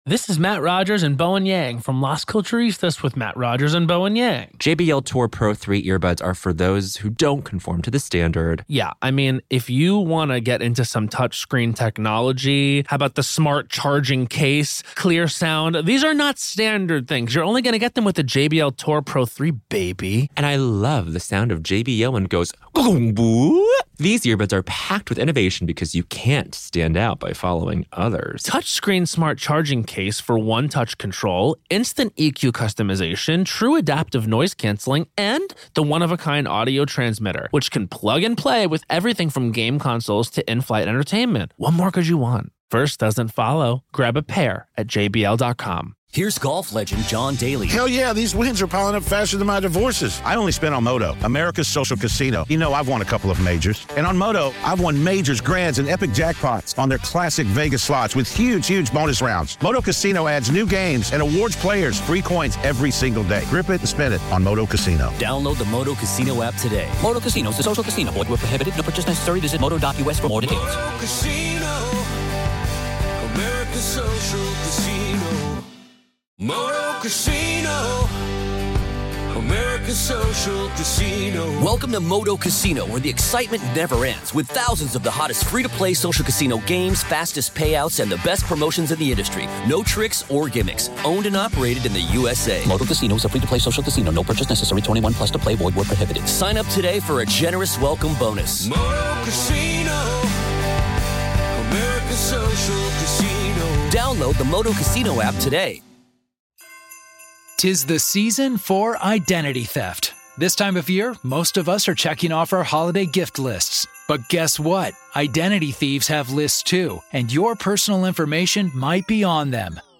In this ongoing Hidden Killers courtroom-coverage series, we present the raw sounds of justice — no commentary, no edits — just the voices of attorneys, witnesses, and the judge as the case unfolds in real time.